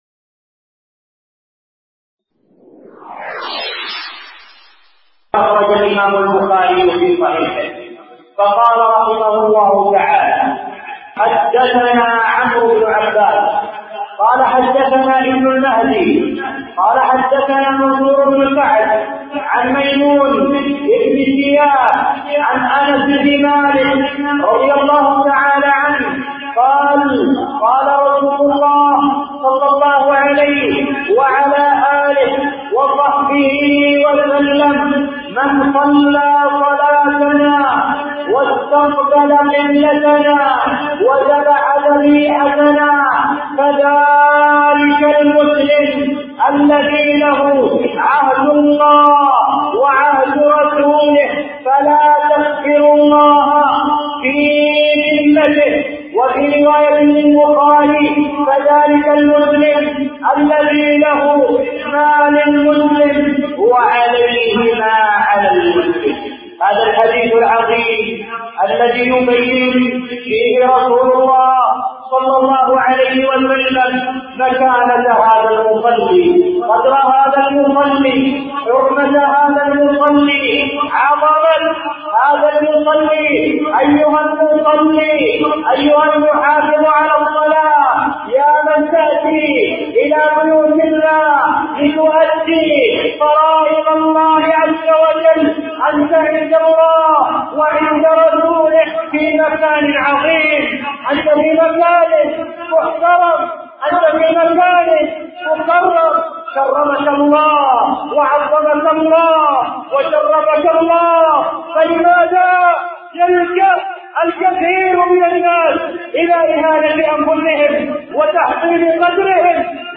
من خطبة
ألقيت بمسجد الرحمن مدينة الجراحي الحديدة اليمن